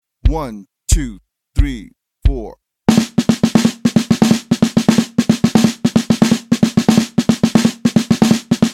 Besetzung: Instrumentalnoten für Schlagzeug/Percussion